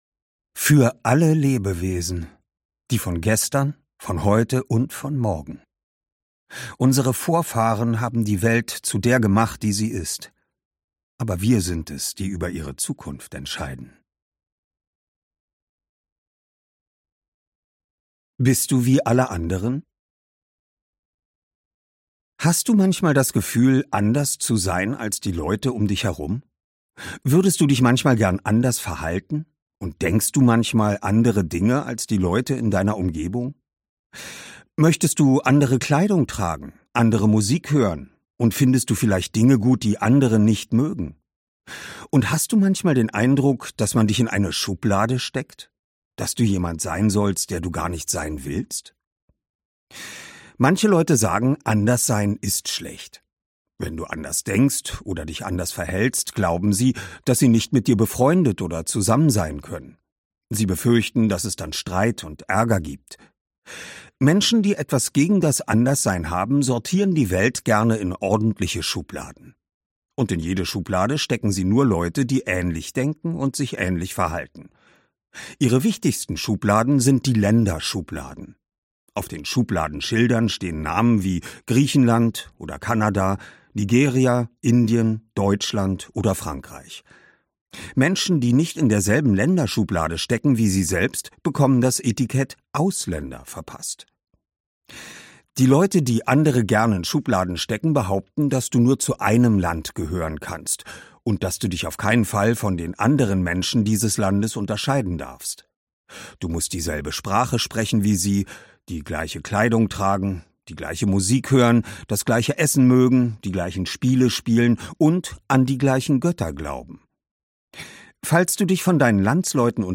Oliver Rohrbeck (Sprecher)
Ungekürzte Lesung